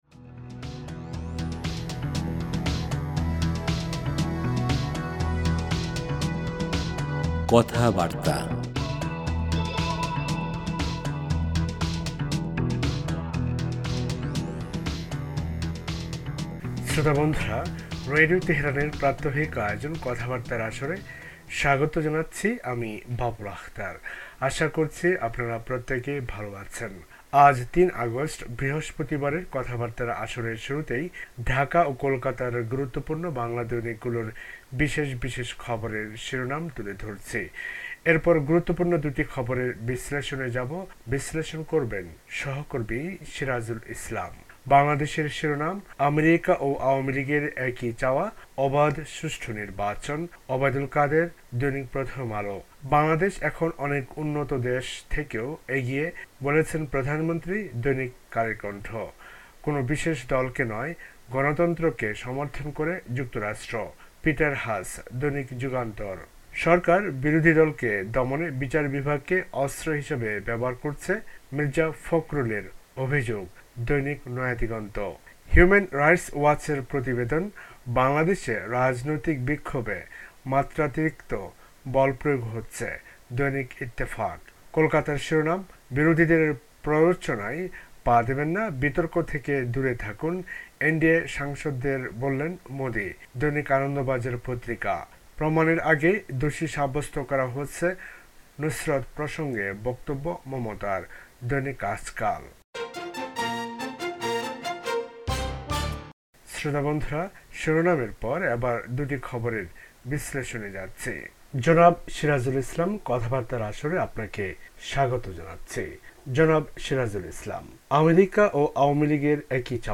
বাংলাদেশ ও ভারতের পত্রপত্রিকার গুরুত্বপূর্ণ খবর